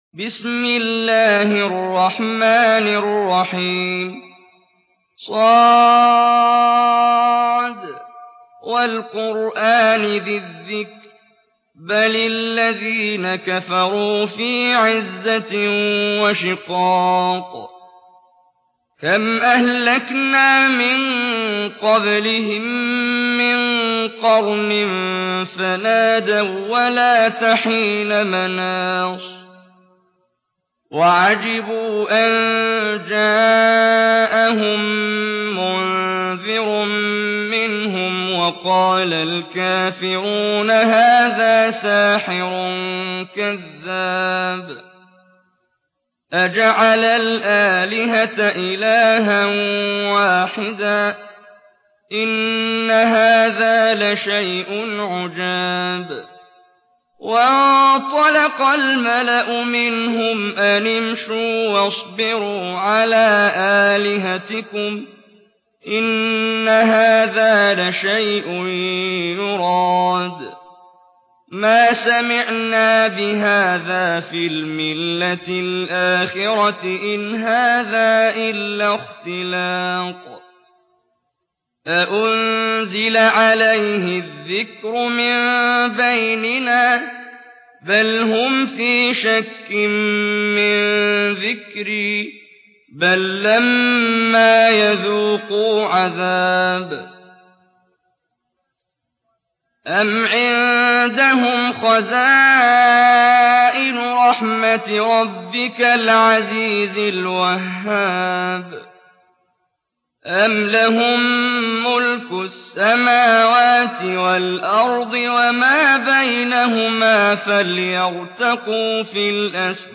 قرآن - قاری محمد جبريل